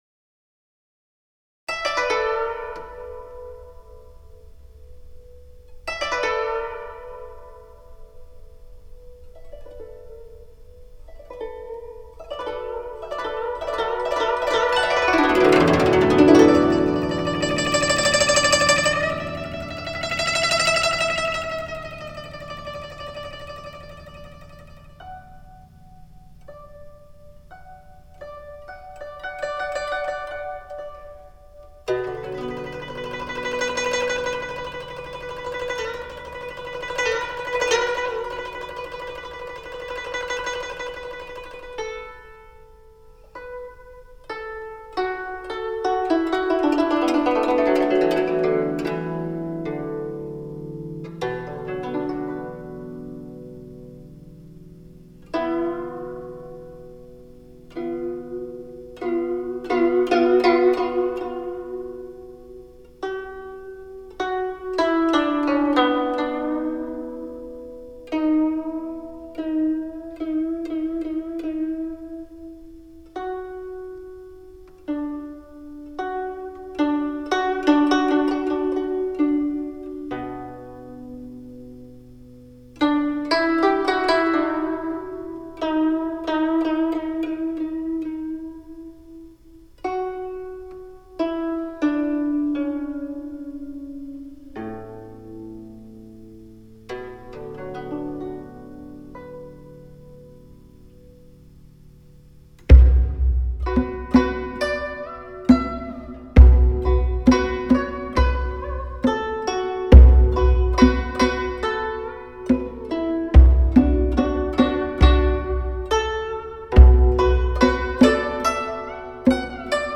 2周前 纯音乐 5